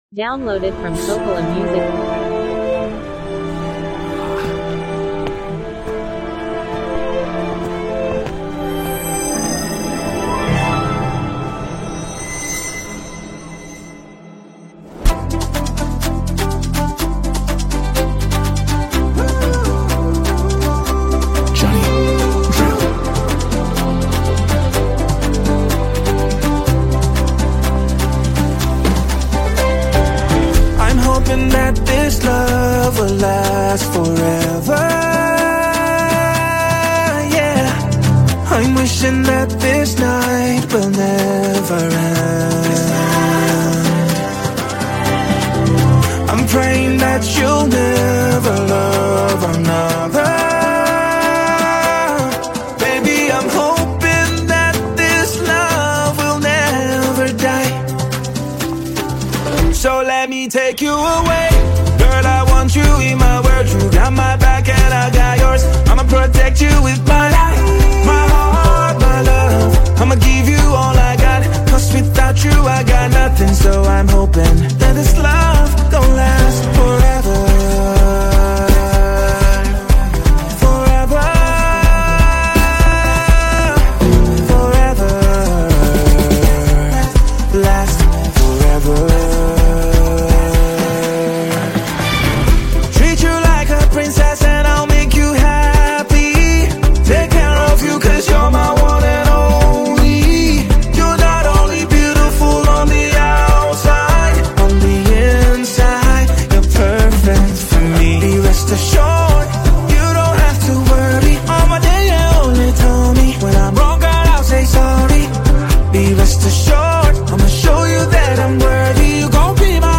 heartfelt love song
gentle instrumentation